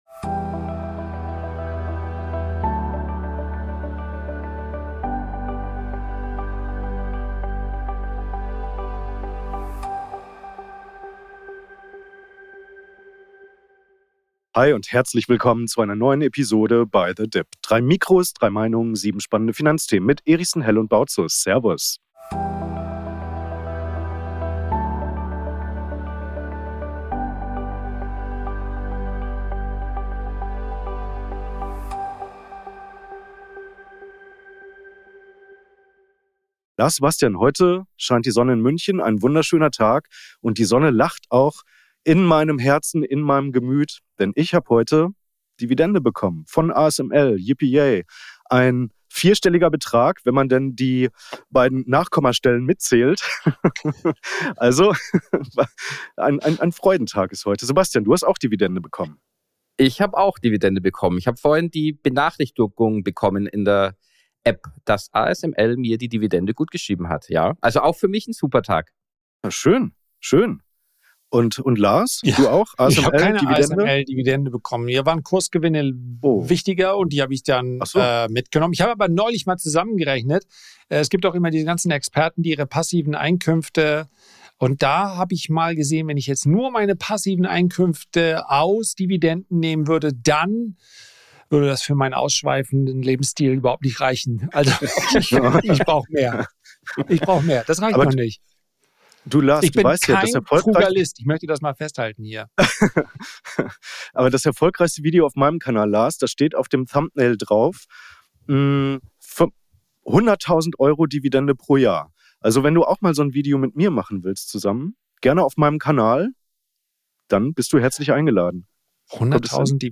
3 Mikrofone, 3 Meinungen